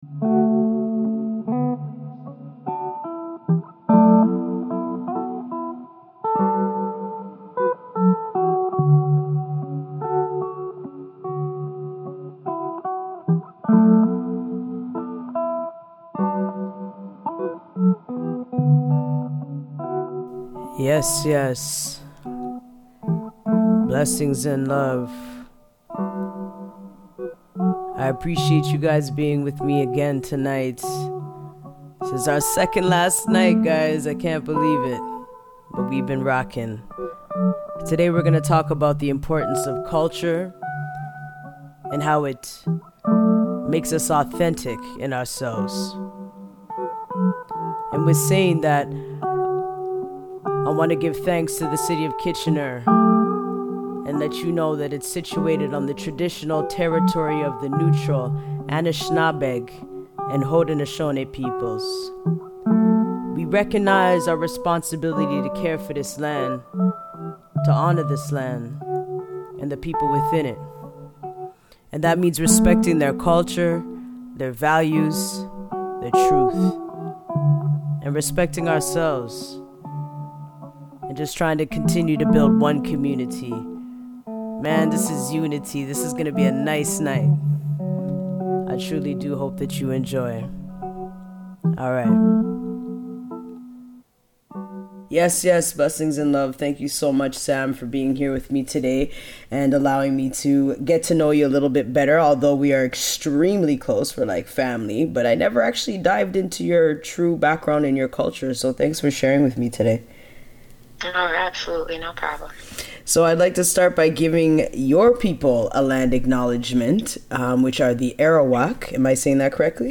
(SOCA CHUTNEY)